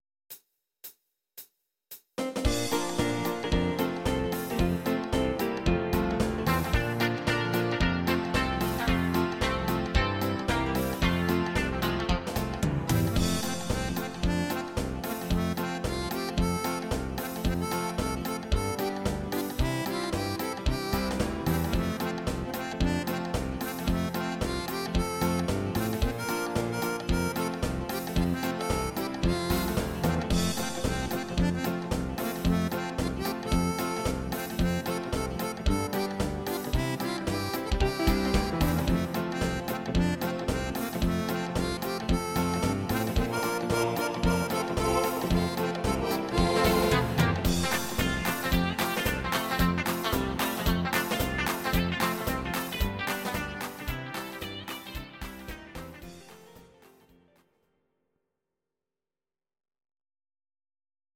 These are MP3 versions of our MIDI file catalogue.
Please note: no vocals and no karaoke included.
Your-Mix: Country (822)